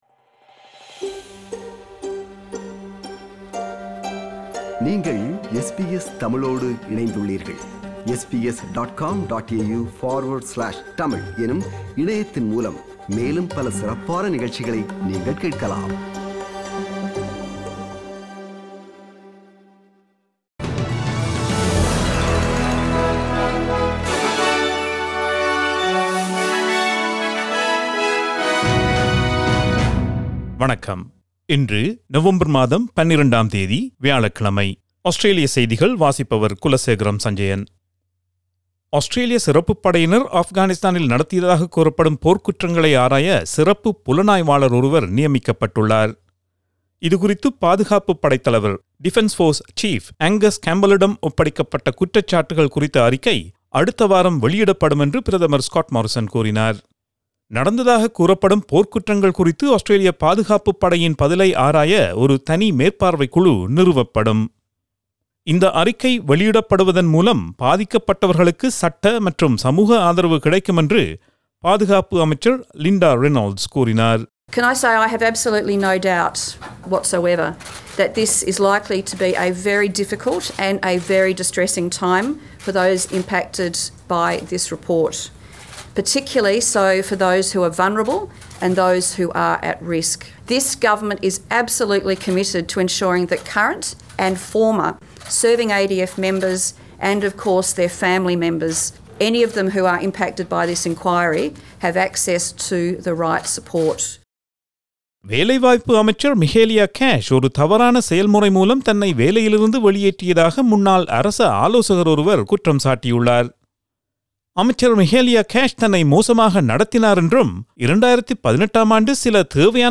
Australian news bulletin for Thursday 12 November 2020.